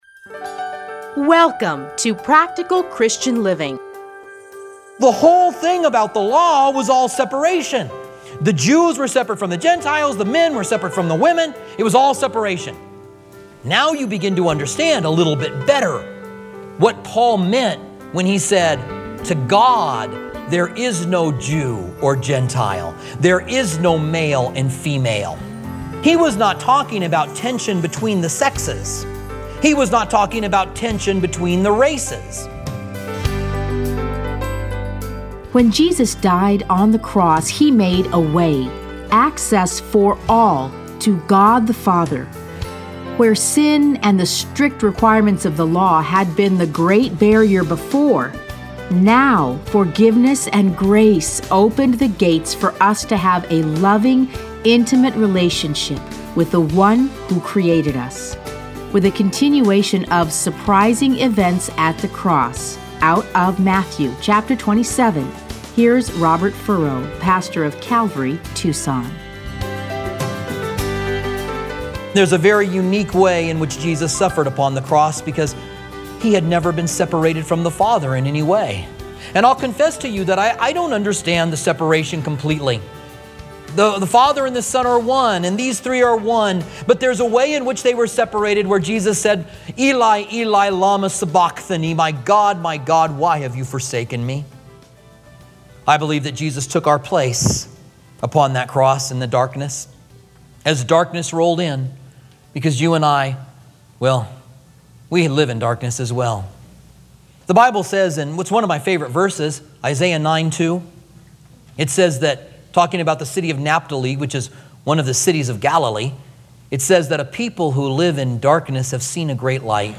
Listen to a teaching from Matthew 15:27:25, 50-53.